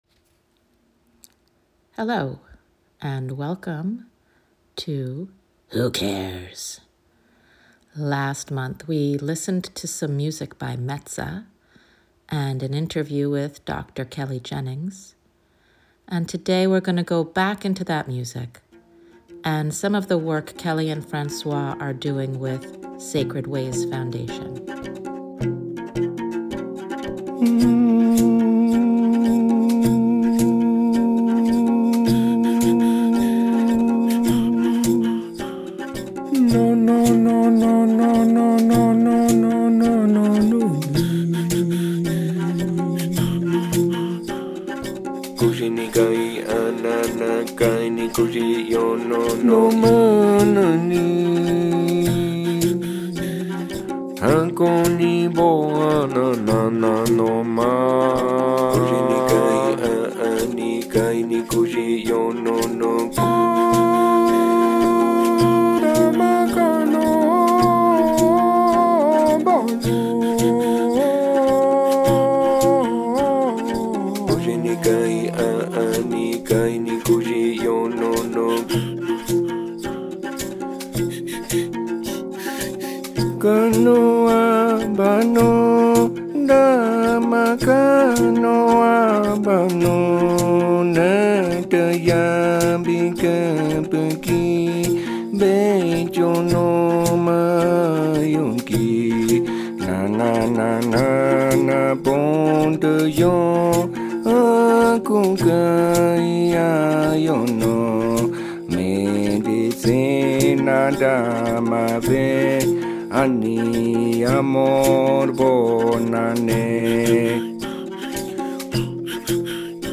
is an interview-based segment that is a mini-deep dive into the world of care, what we care about...and maybe why.